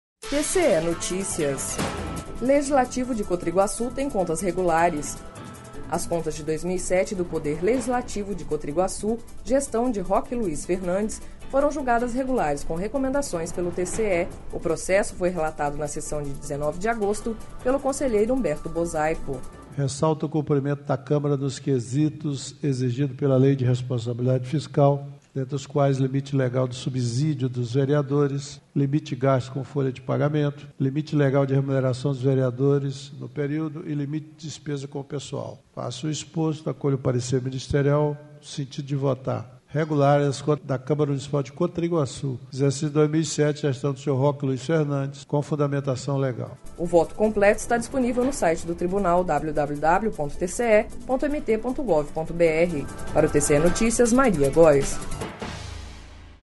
Sonora: Humberto Bosaipo – conselheiro TCE-MT